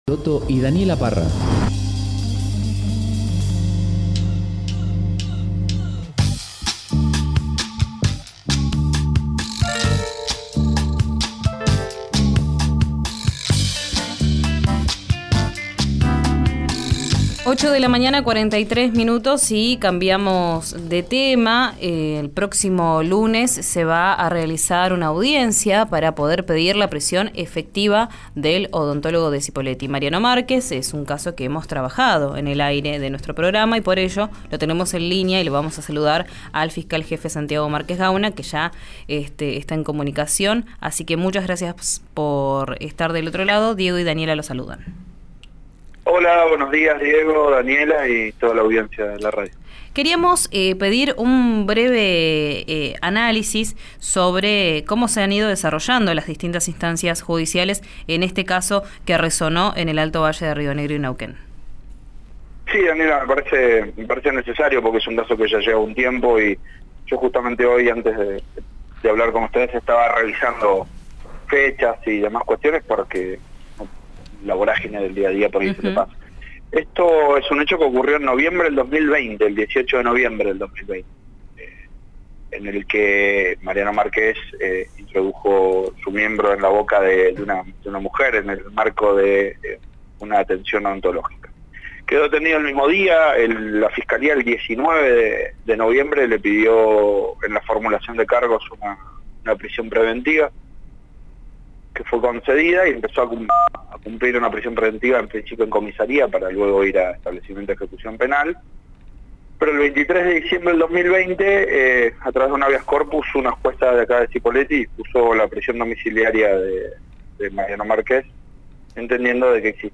En declaraciones a «Vos al Aire» en RÍO NEGRO RADIO, el fiscal contó que solicitó una nueva revisión porque considera que el odontólogo debe cumplir la cautelar en un penal porque la condena está firme en la justicia provincial.